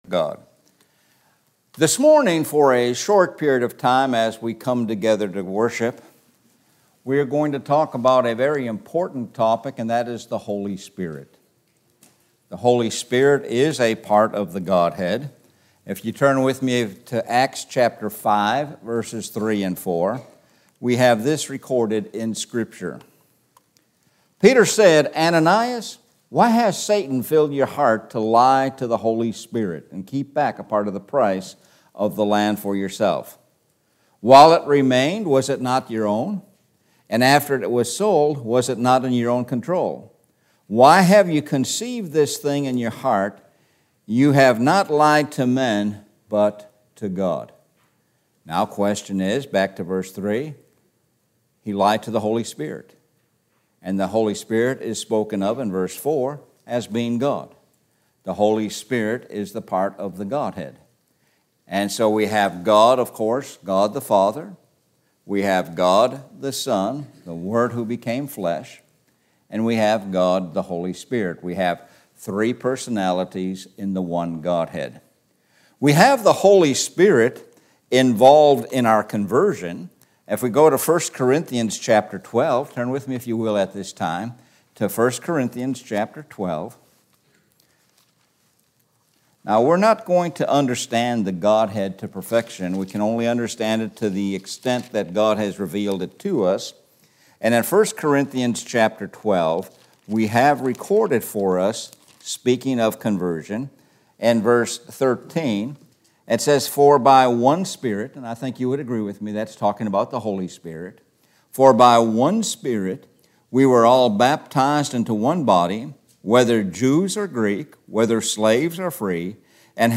Sun Am Sermon – The Holy Spirit – 07.30.23